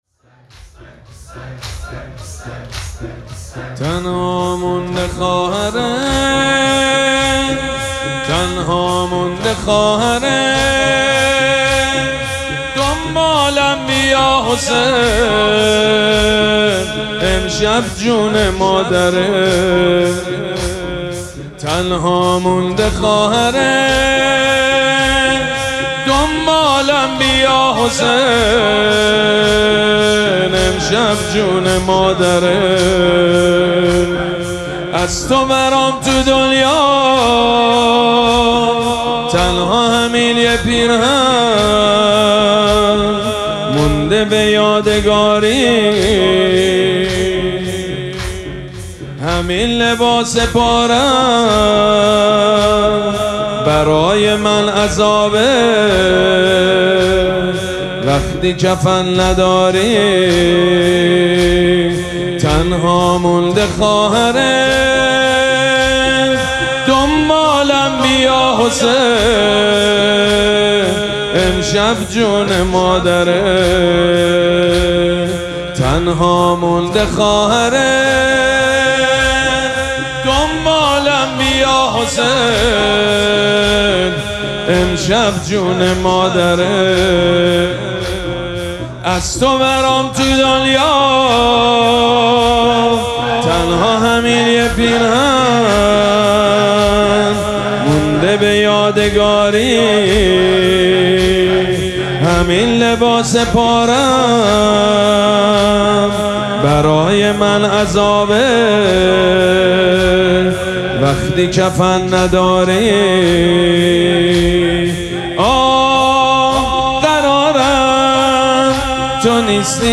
مراسم عزاداری شام شهادت حضرت زینب سلام‌الله‌علیها پنجشنبه ۲۷ دی ماه ۱۴۰۳ | ۱۵ رجب ۱۴۴۶ حسینیه ریحانه الحسین سلام الله علیها
سبک اثــر زمینه مداح حاج سید مجید بنی فاطمه